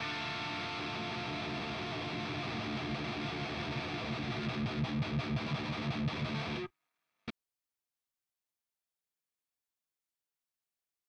Akkorde mit hohen Frequenzen überlagern tiefe e-Saite bei parallelem palmmuting (Tonex-VST)